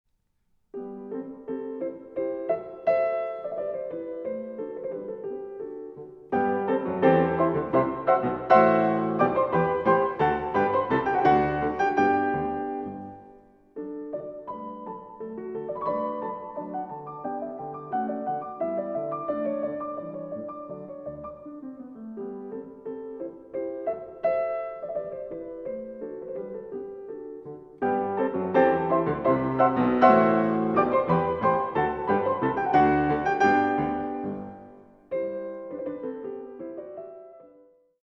for piano 4 hands